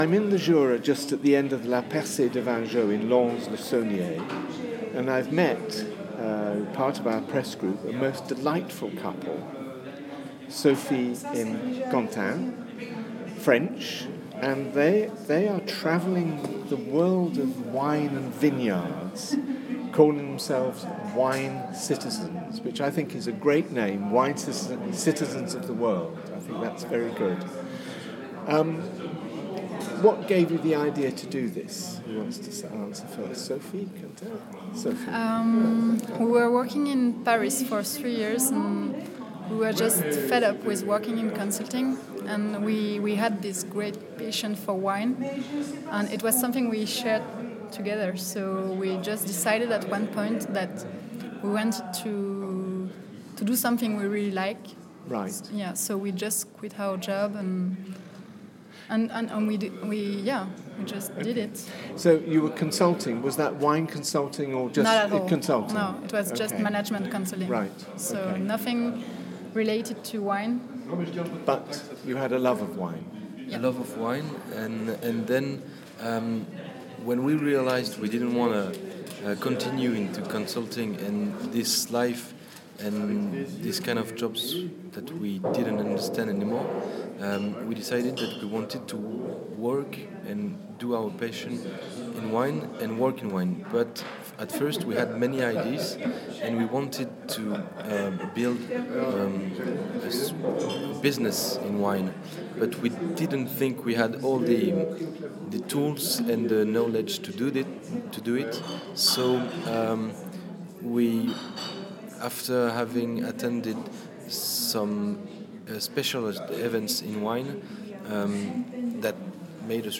The Wine Citizens, a young French couple, tell me all about their adventures in wine - an odyssey which will last three years,